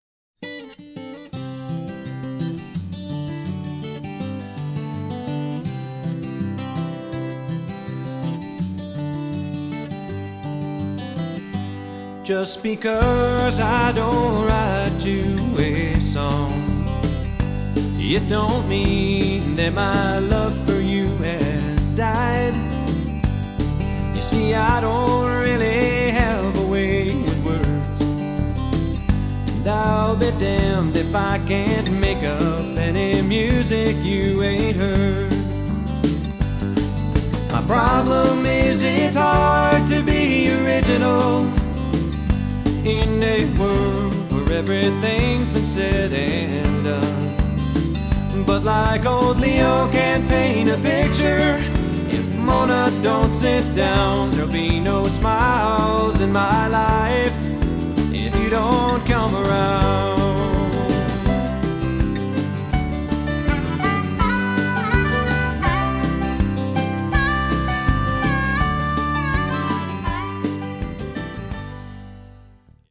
Recorded & Mixed at Avalanche, Northglenn, CO.
Fresh sounds evoke familiar feelings.